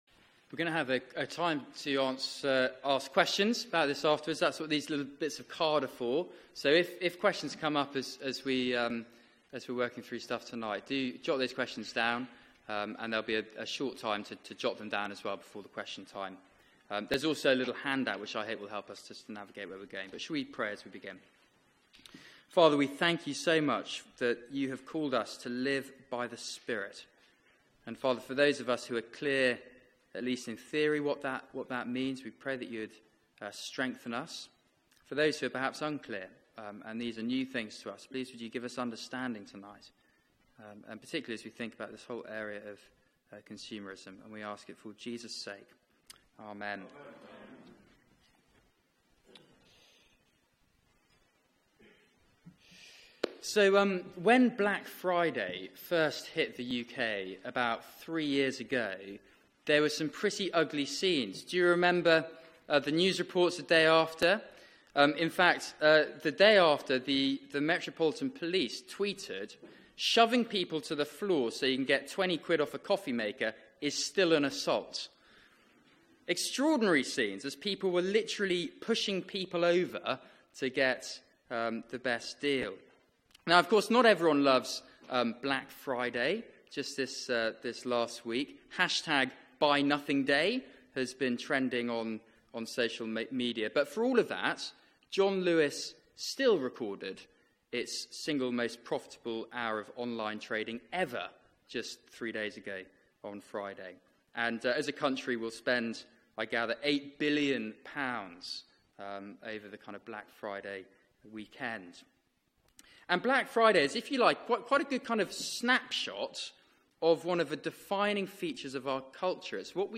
Media for 6:30pm Service on Sun 26th Nov 2017 18:30 Speaker
Galatians 5:16-26 Series: The Spirit's fruit in today's world Theme: Peace and joy in a consumerist culture 1. Sermon 1.